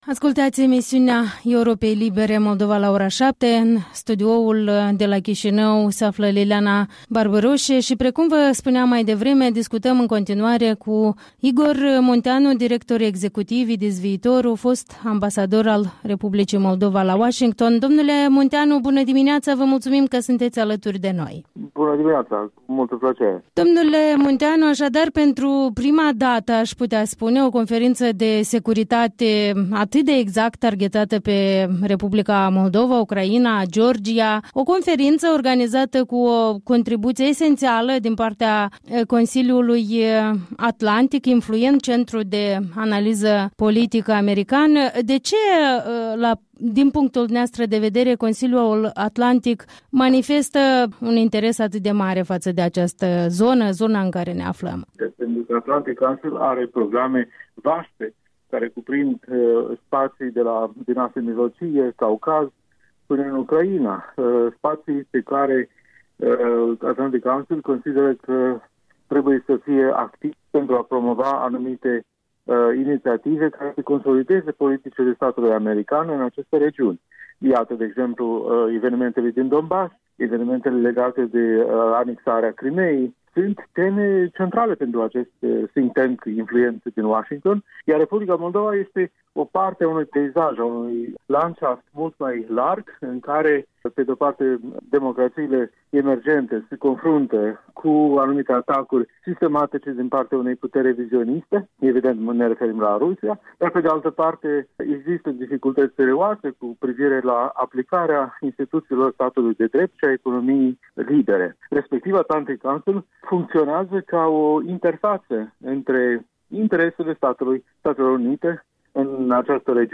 Interviul dimineții cu directorul Institutului IDIS Viitorul despre Conferința de Securitate de la Chișinău.